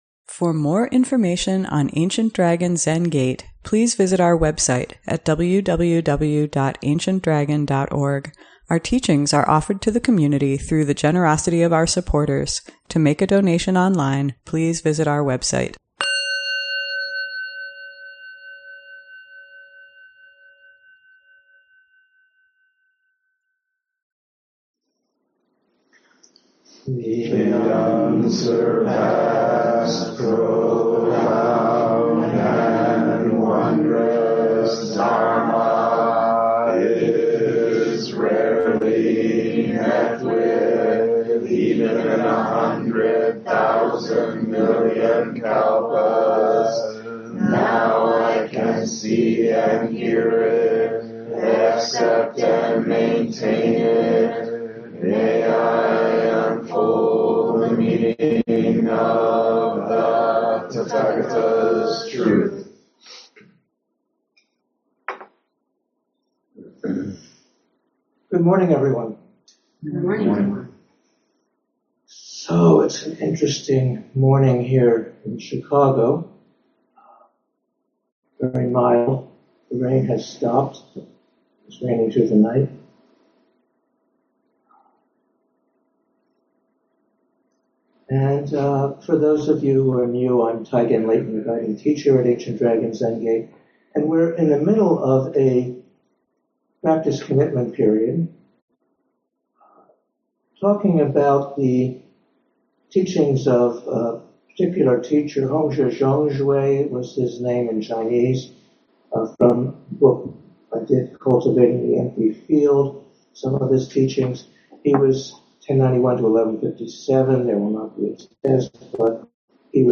ADZG Sunday Morning Dharma Talk